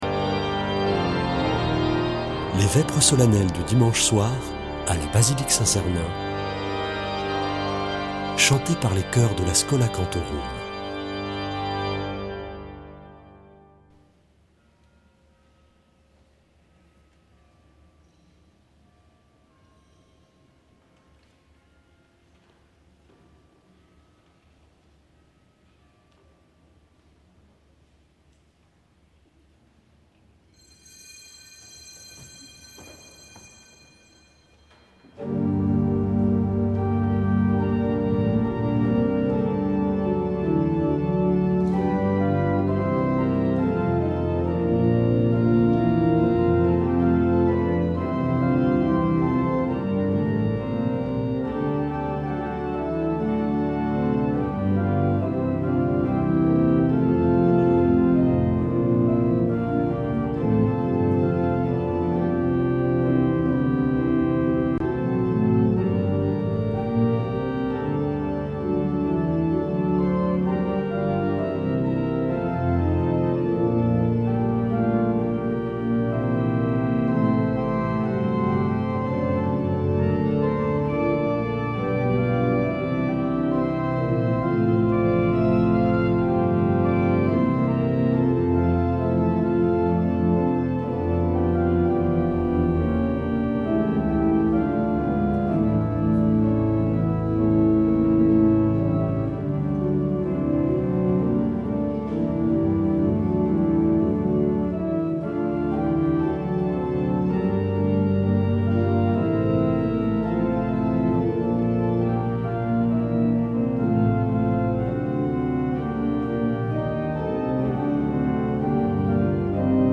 Vêpres de Saint Sernin du 16 juin
Une émission présentée par Schola Saint Sernin Chanteurs